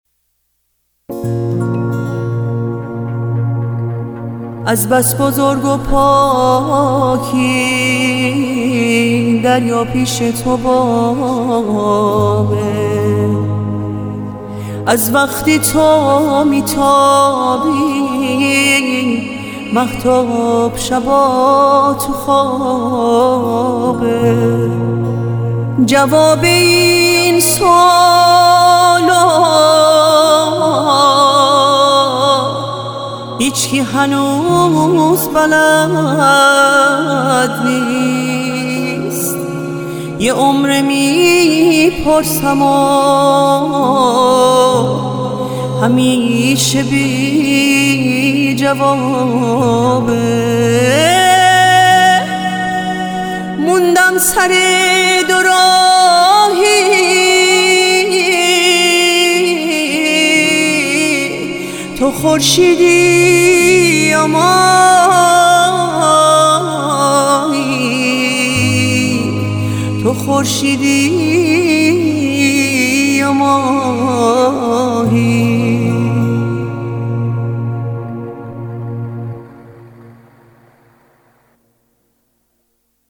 آهنگ فارسی